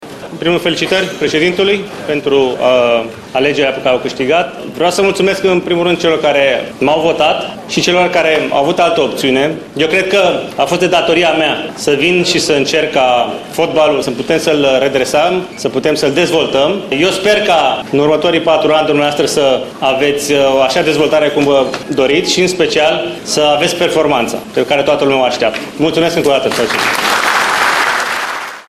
Ionuț Lupescu a părut ușor derutat, dar și resemnat, dar a avut forța să îl felicite pe învingător:
18-apr-Sile-Lupescu-resemnat.mp3